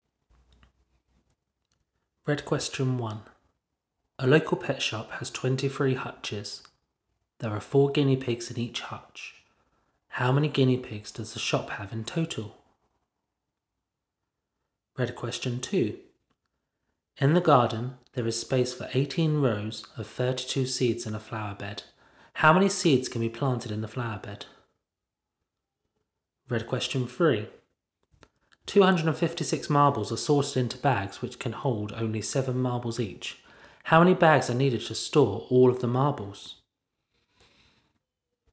Here are some audio clips of the questions being read out loud.